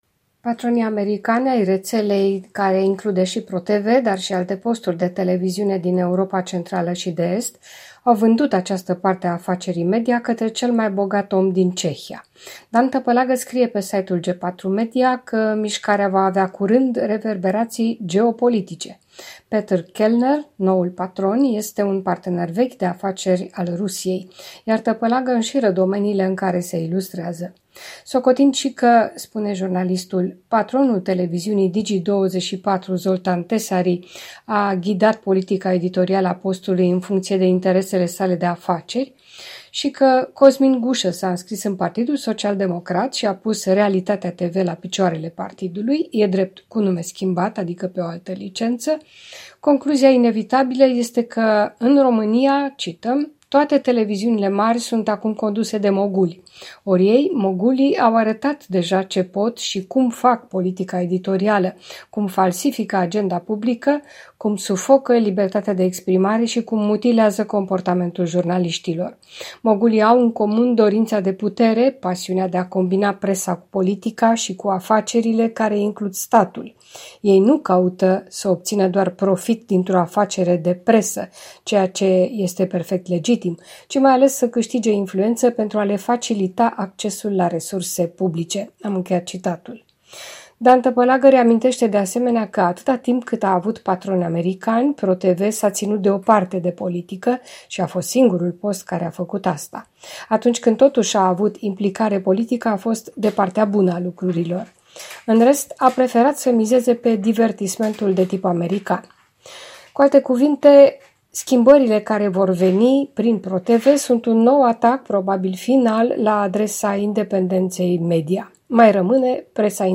Revista presei de la București.